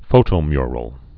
(fōtō-myrəl)